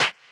perc03.ogg